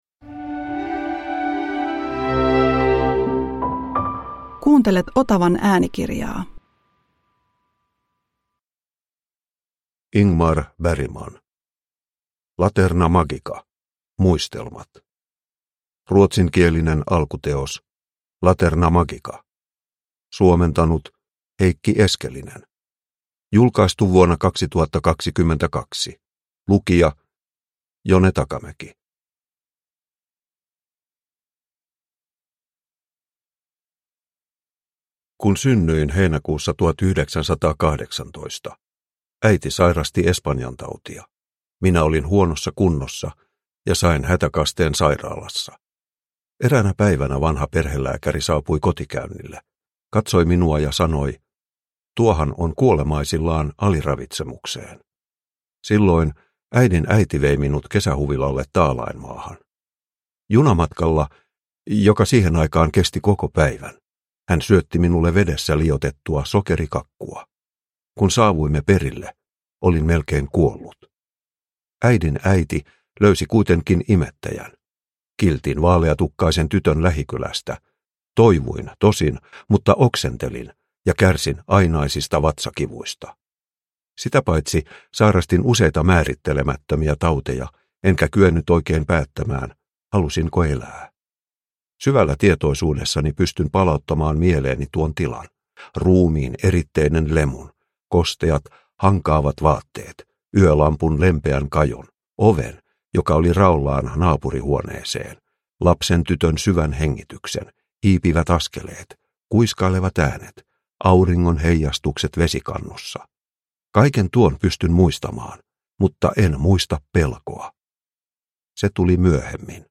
Laterna magica – Ljudbok – Laddas ner